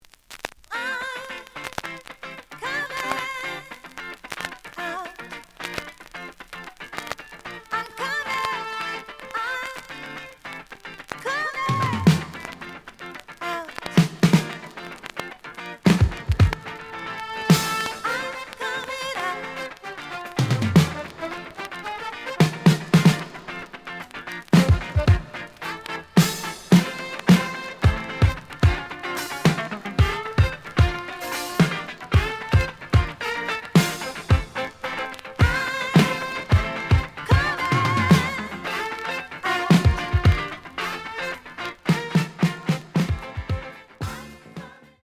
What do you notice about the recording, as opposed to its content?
The audio sample is recorded from the actual item. Some periodic noise on beginning of A side due to scrathces.